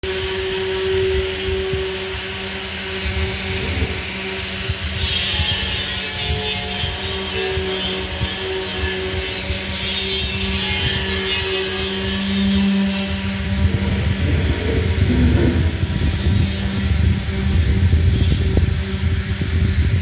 아쉬우나마 돌 주변의 소리를 담아본다.